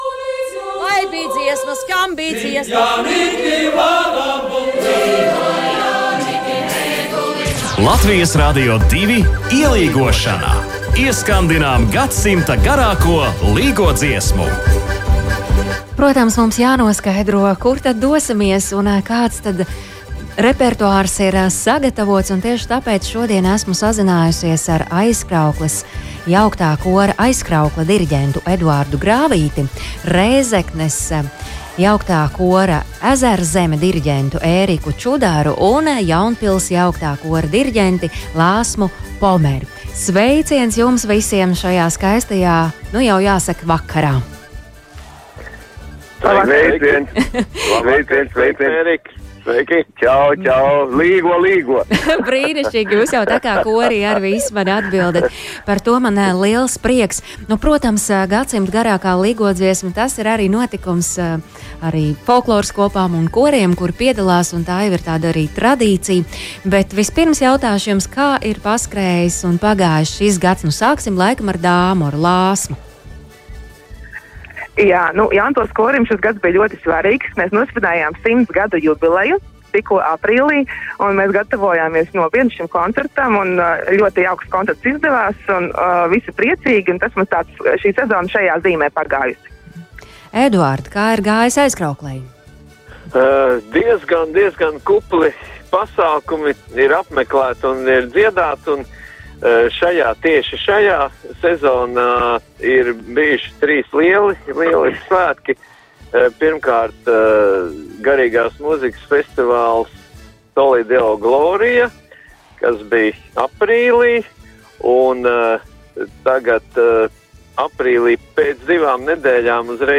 Tieši dienas vidū, plkst.12.45 tai pievienosies jauktais koris „Aizkraukle”,  bet plkst.14.15 Gadsimta garākā līgodziesma LR2 ēterā atskanēs Jaunpils jauktā kora sniegumā.